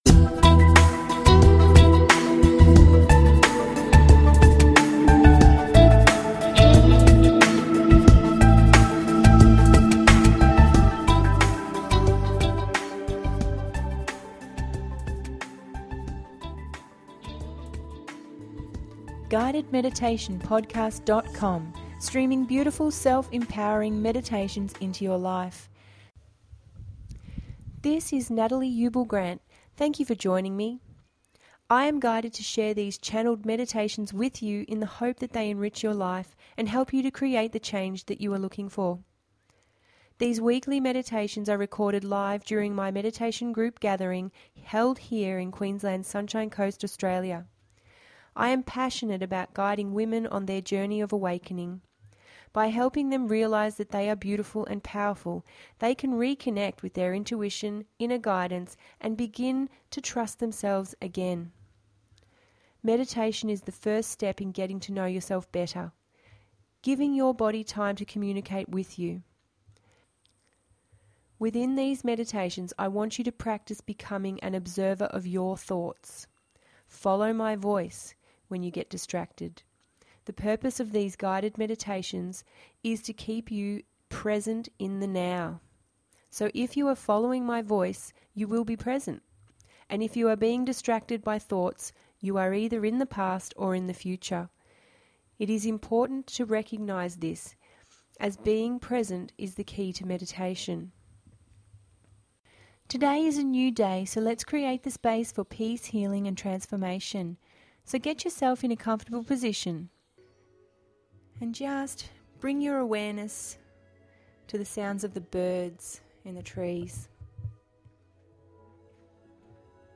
Gently relax to the sound of the waves rolling in. This is an opportunity to set a new intention and begin the week with positivity.
I hope you enjoy this relaxing meditation
008-the-beach-guided-meditaion-podcast.mp3